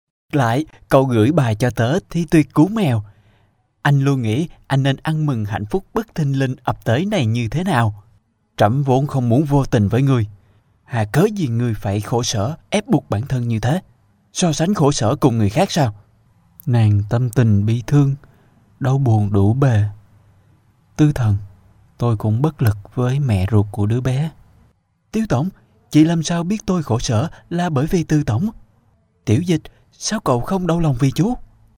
越南语男5mrtk_外语_小语种_样音1_成熟.mp3